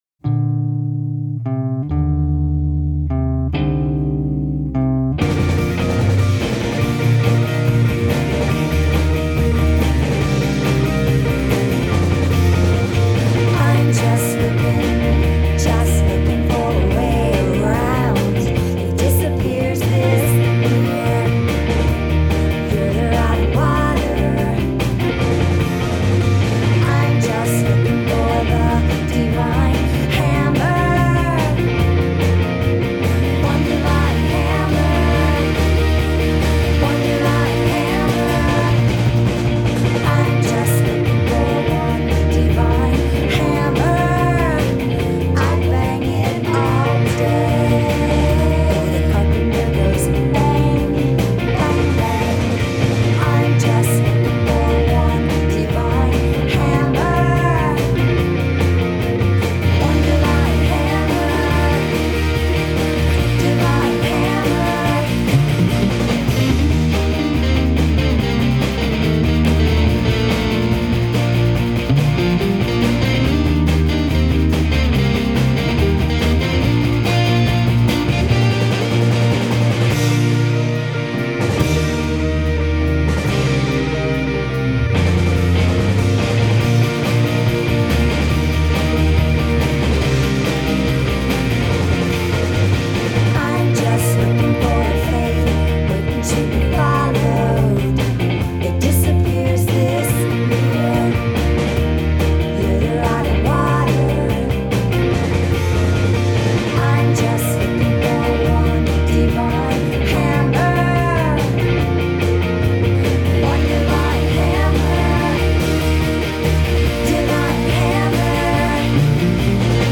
Инди рок